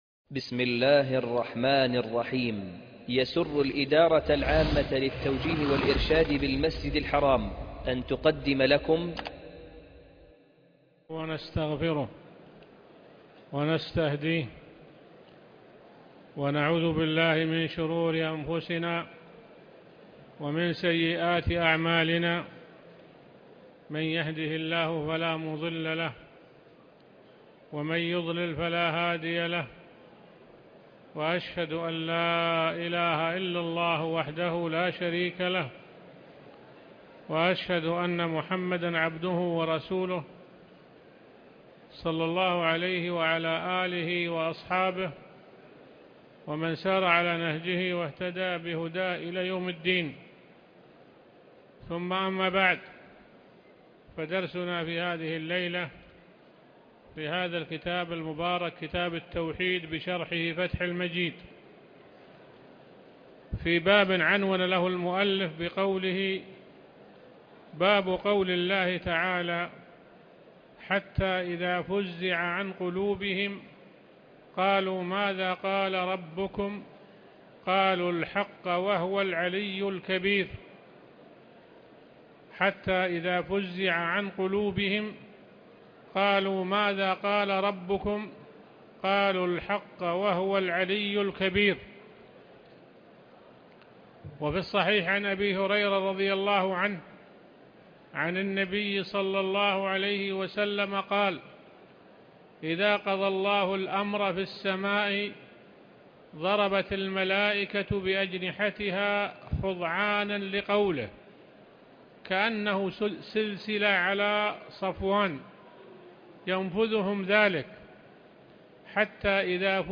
الدرس 17 (فتح المجيد